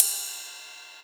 VEC3 Cymbals Ride 13.wav